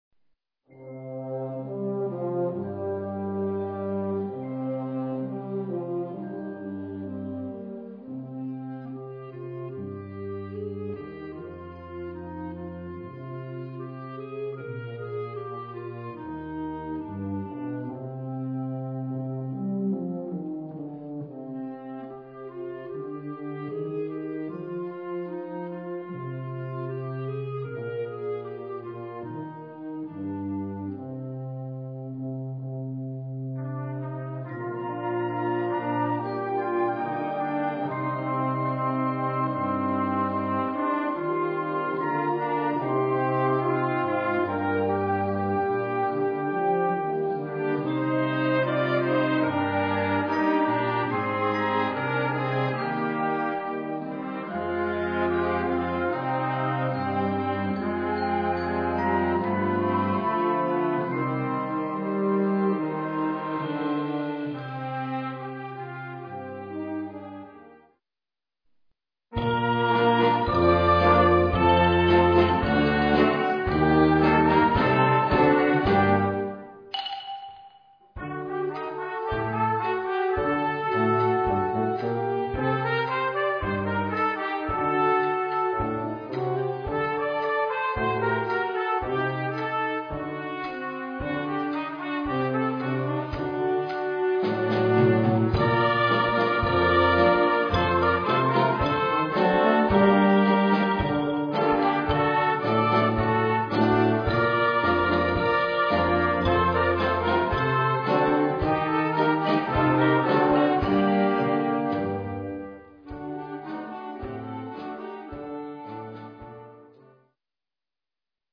Categorie Harmonie/Fanfare/Brass-orkest
Subcategorie Internationale folklore
Bezetting Ha (harmonieorkest); YB (jeugdorkest)